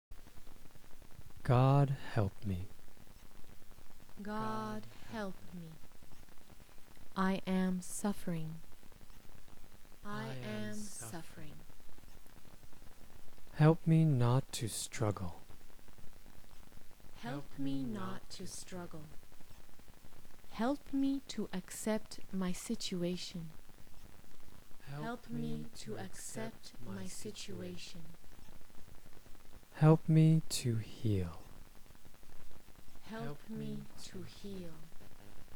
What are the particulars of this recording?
The following prayers are recorded in a "call and response" format : we speak, then you can repeat.